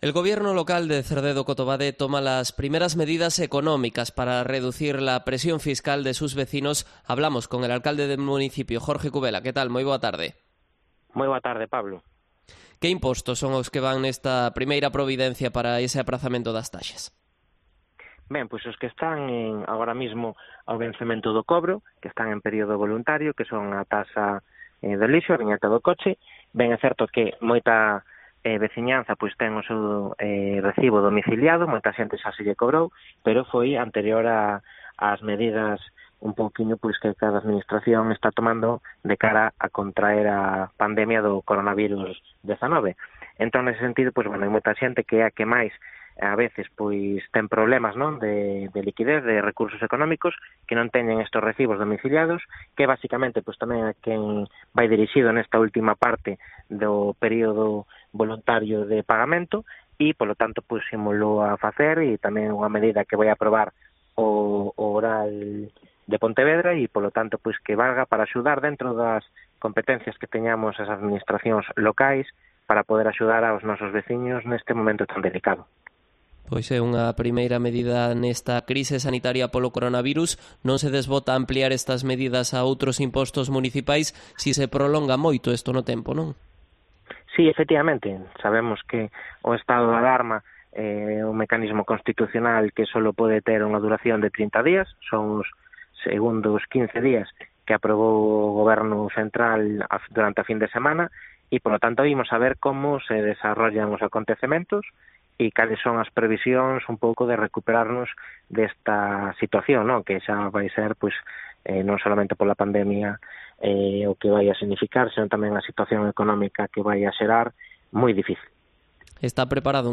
Entrevista con el alcalde de Cerdedo-Cotobade, Jorge Cubela